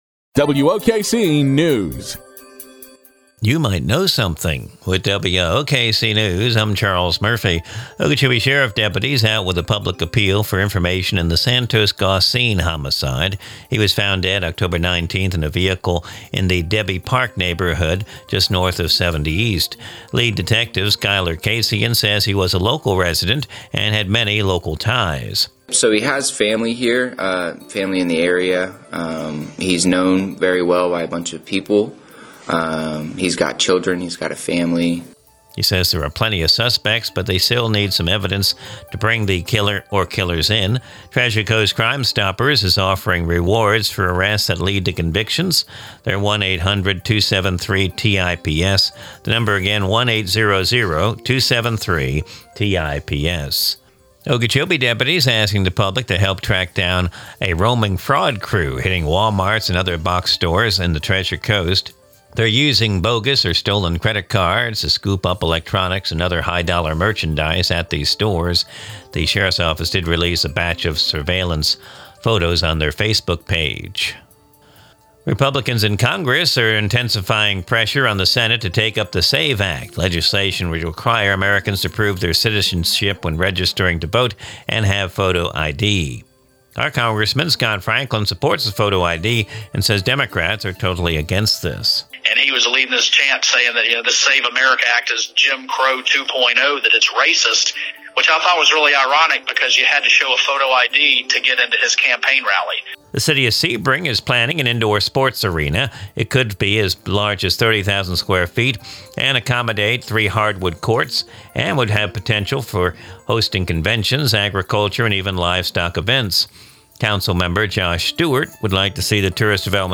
Recorded from the WOKC daily newscast (Glades Media).